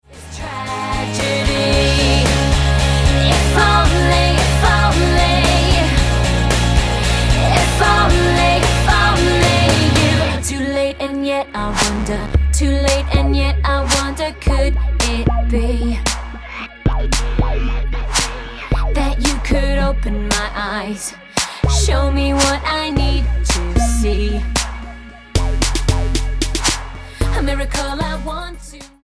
MOR / Soft Pop
Style: Pop Approach: Praise & Worship